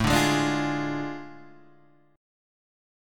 A Major 7th Suspended 4th Sharp 5th
AM7sus4#5 chord {5 5 6 7 6 4} chord